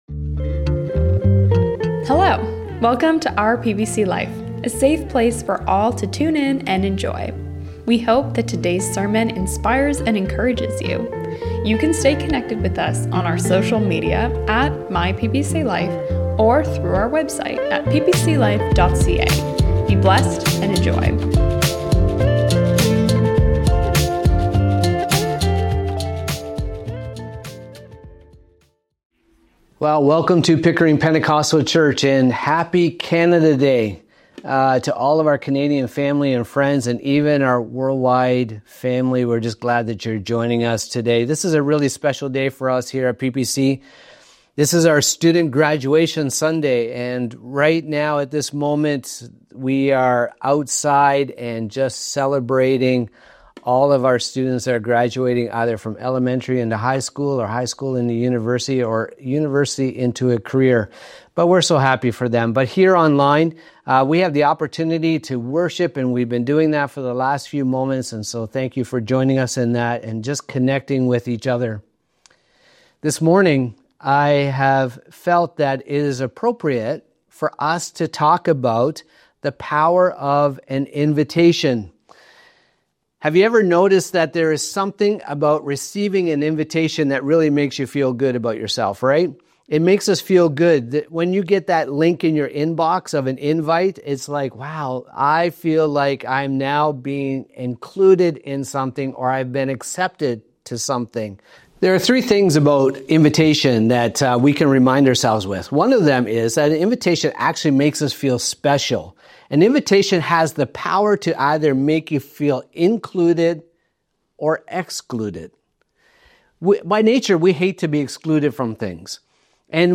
We trust that this message will be beneficial to you today!